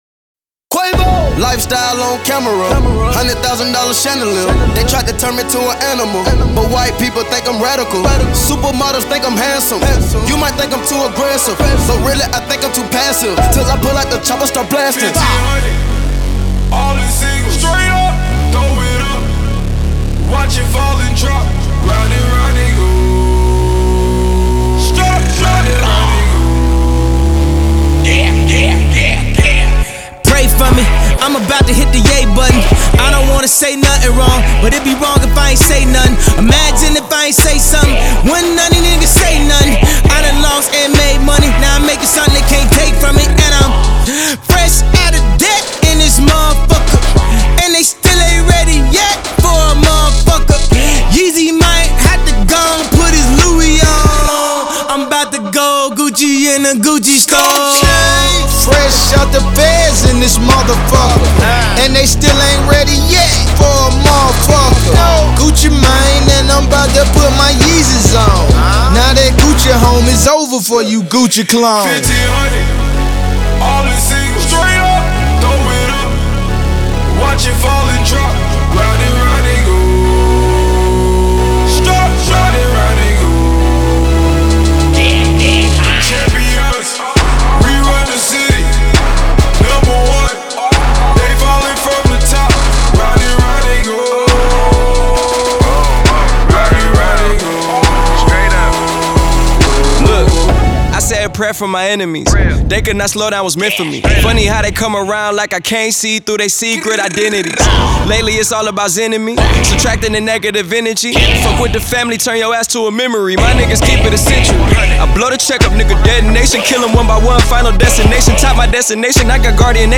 это мощный трек в жанре хип-хоп